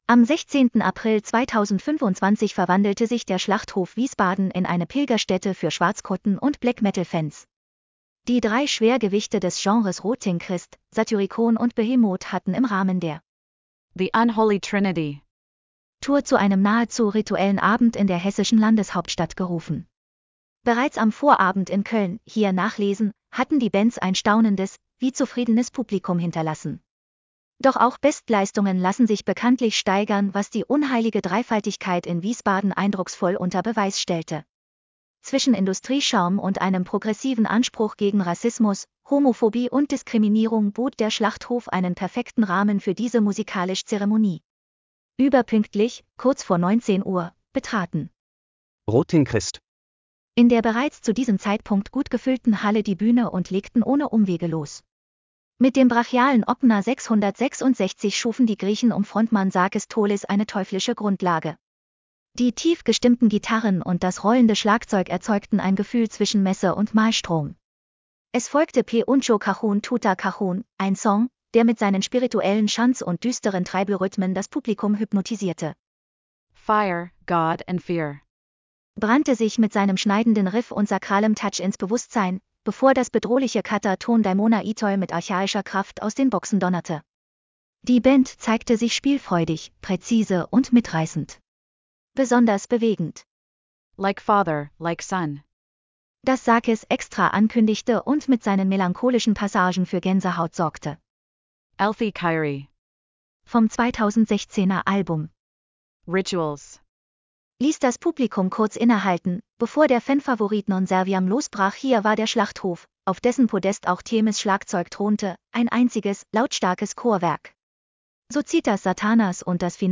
Lass Dir den Beitrag vorlesen: /wp-content/TTS/192135.mp3 Bereits am Vorabend in Köln (hier nachlesen) hatten die Bands ein staunendes, wie zufriedenes Publikum hinterlassen.